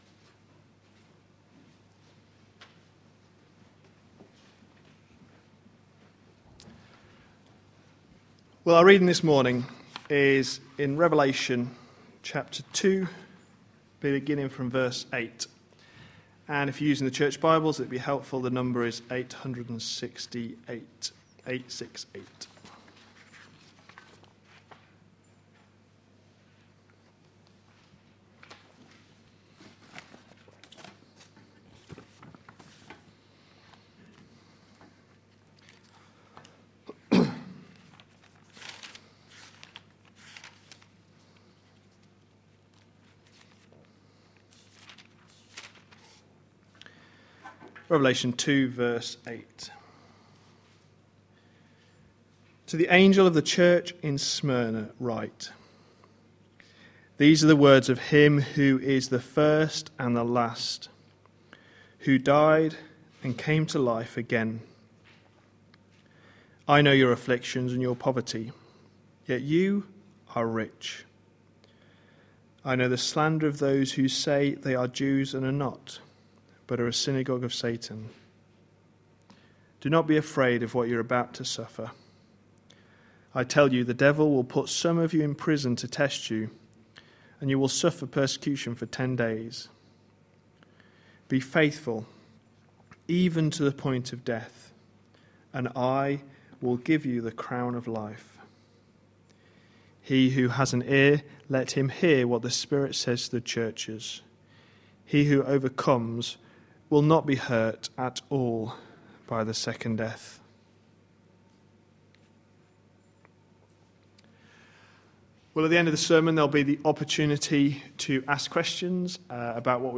A sermon preached on 29th December, 2013.